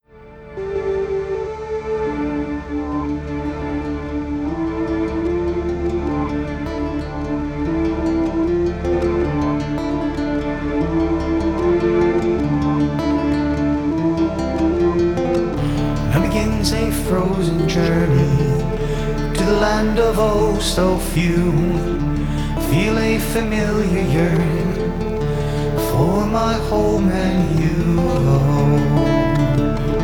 Synthesizer
Optigon
Acoustic guitar, Cello
Lead Vocal